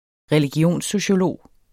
Udtale [ ʁεliˈgjoˀnsoɕoˌloˀ ]